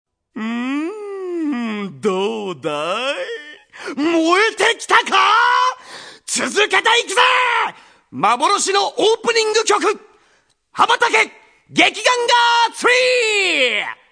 Seki Tomokazu-sanGai Daigohji's seiyuu (or voice actor) is none other than Seki Tomokazu, my third favorite seiyuu.
These sounds are from the Gekiganger Karaoke section of the soundtrack where Gai introduces each song for the karaoke section.
Gai's Hotblooded Talk #2 - Gai introduces the second song, Habatake Gekiganger 3!